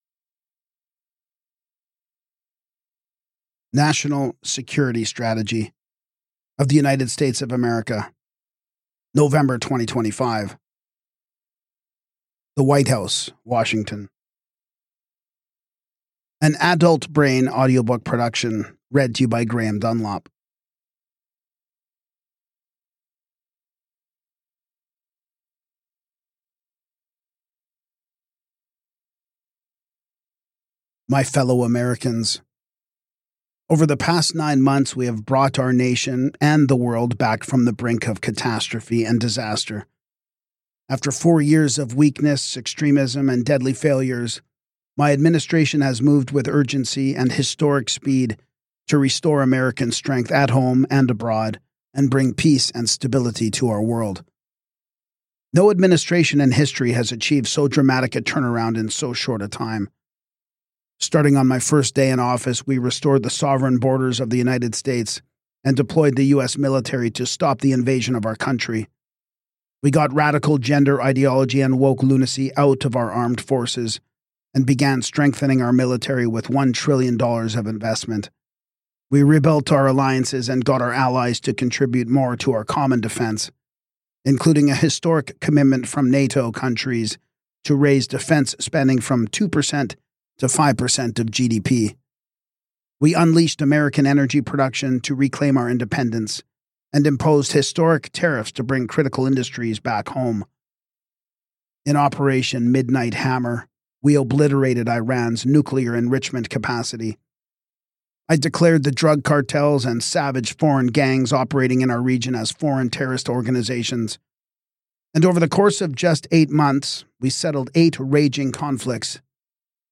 National Security Strategy 2025 Audiobook | Full Government Document
This recording presents a full narration of the National Security Strategy of the United States of America issued under President Donald J. Trump. The document outlines America’s national security priorities, strategic objectives, and approach to defending the nation, its people, and its interests.